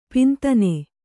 ♪ pintane